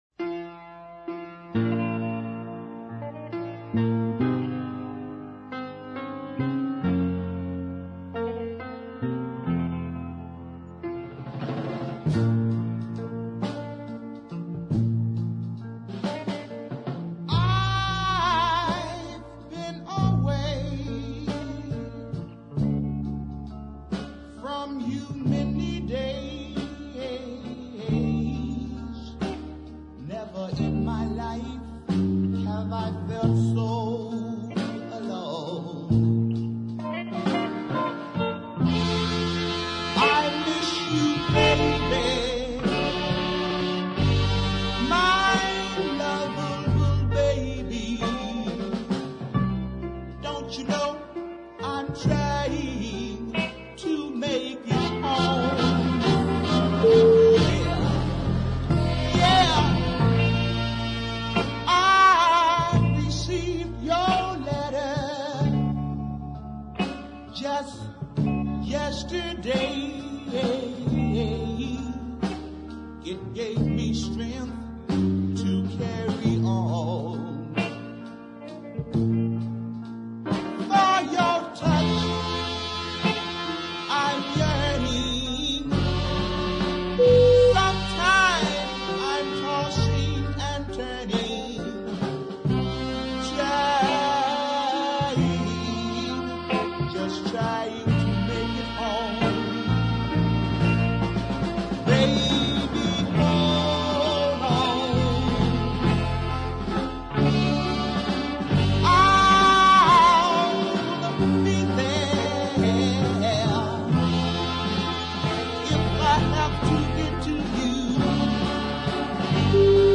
fine deep ballad
has a finely wrought melody